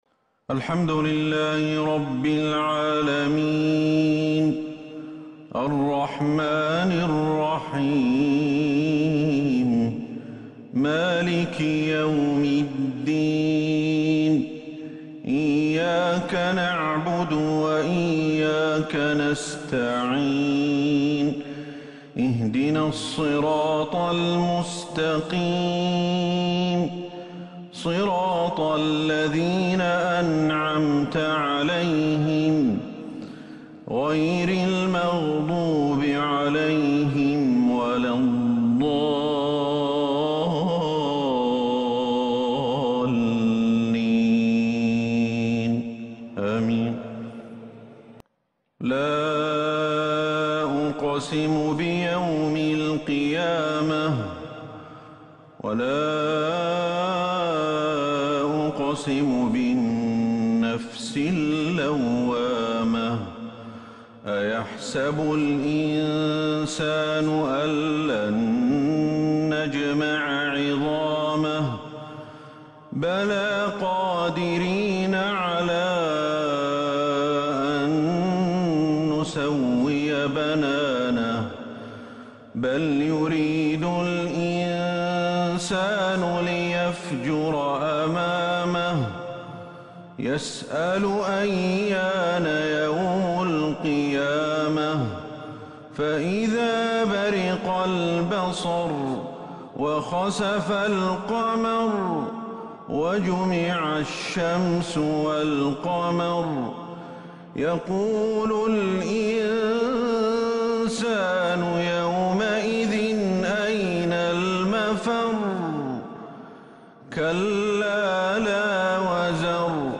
عشاء 19 جمادى الأولى 1442 هـ سورة {القيامة} > 1442 هـ > الفروض